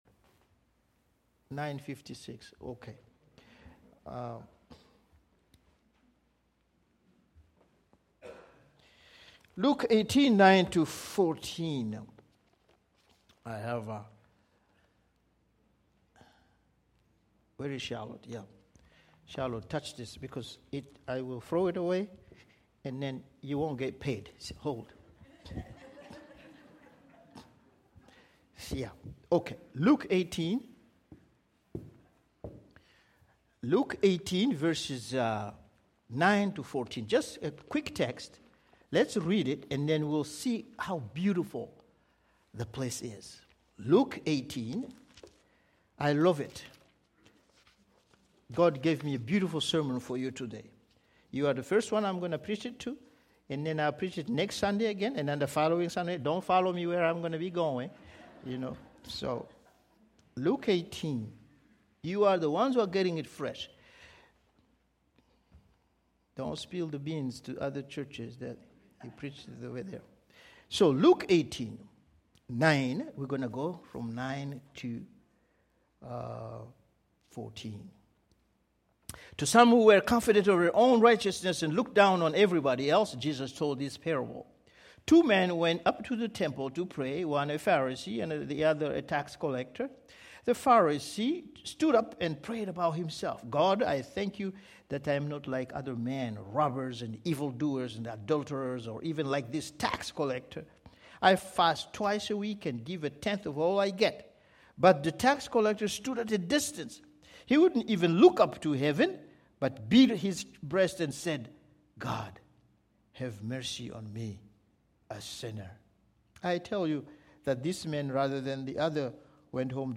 Luke 18:9-14 Worship Service Video August 4 Audio of Message « Prayer That Makes Room in Your Life the Bible